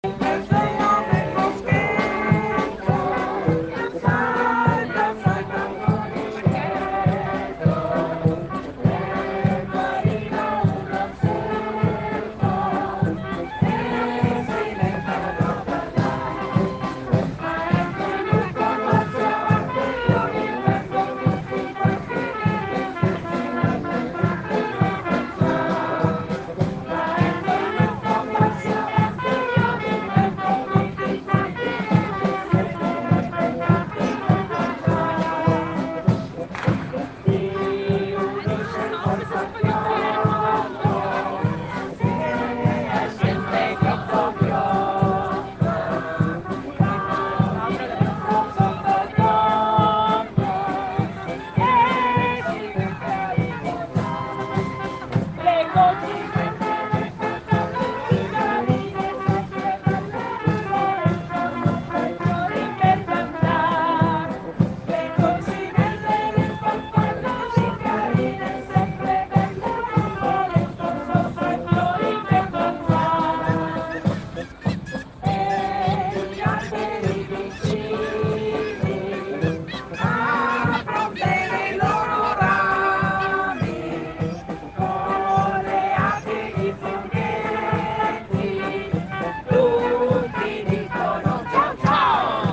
SFILATA DI CARNEVALE (gennaio – febbraio 2017)
Qui sotto potete ascoltare la canzone (inventata dai bambini) che abbiamo cantato insieme alla Banda Civica di Borgo e vedere le foto del “laboratorio creativo” per i vestiti e della nostra sfilata nel gruppo IL BOSCO DEI BAMBINI.